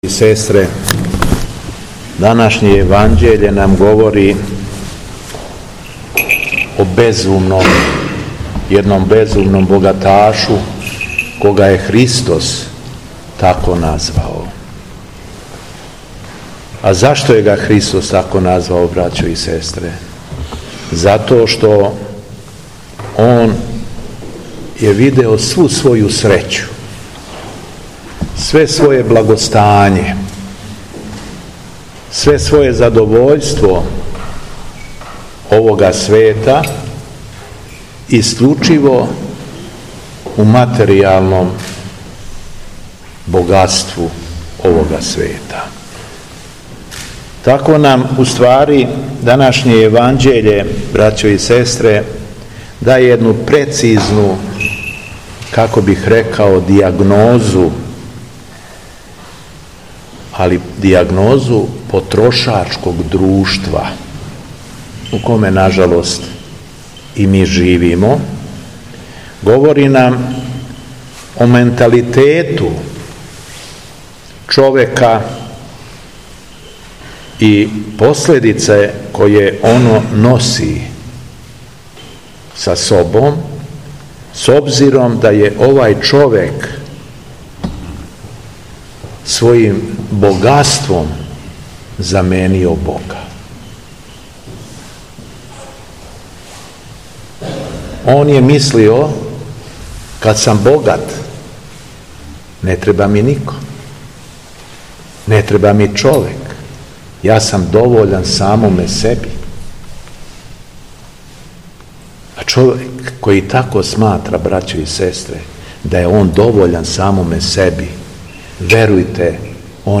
У 26. недељу по Педесетници 3. децембра 2023. године, Епископ шумадијски Г. Јован служио је Свету Архијерејску Литургију у храму Светог апостола Томе...
Беседа Његовог Преосвештенства Епископа шумадијског г. Јована